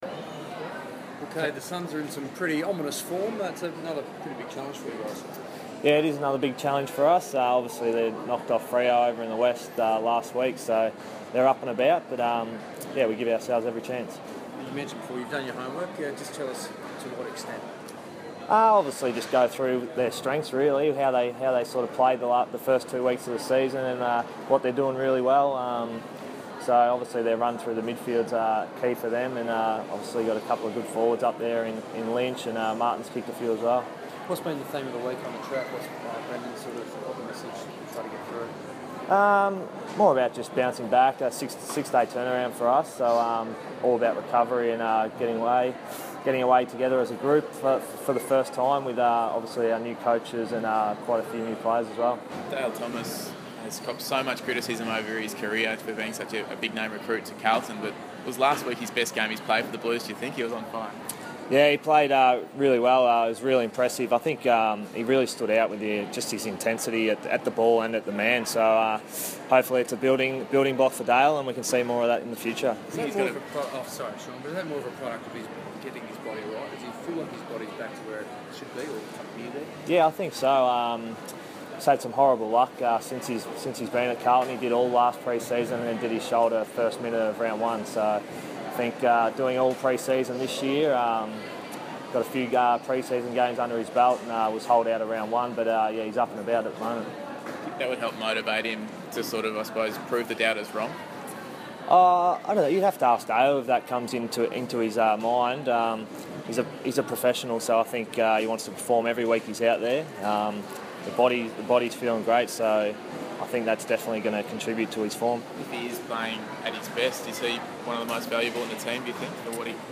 Kade Simpson press conference - April 8
Carlton vice-captain Kade Simpson addresses the media at Melbourne Airport before boarding the team's flight to the Gold Coast.